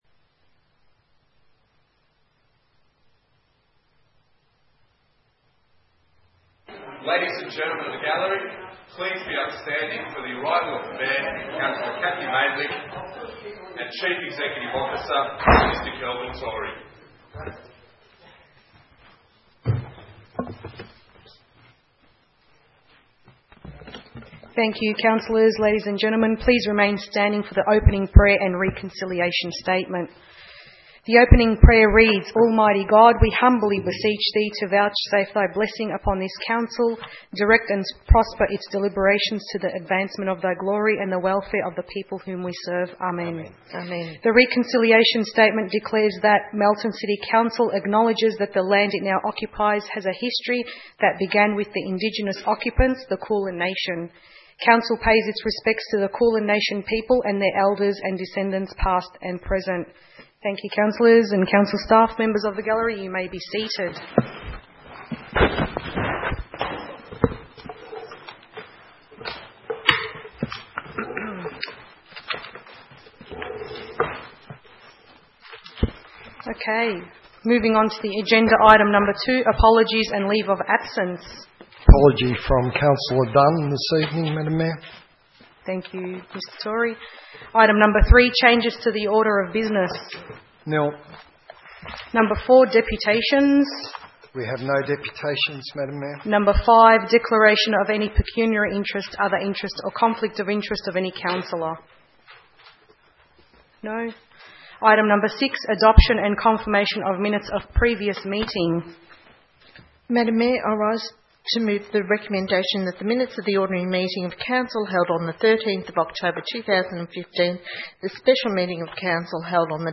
10 November 2015 - Ordinary Council Meeting